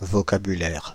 France (Paris)